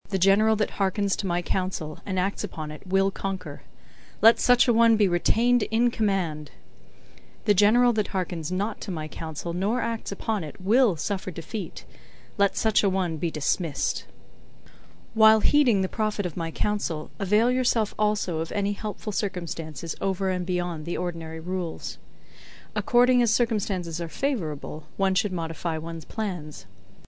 有声读物《孙子兵法》第5期:第一章 始计(5) 听力文件下载—在线英语听力室